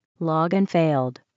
1 channel
crystal.login.failed.mp3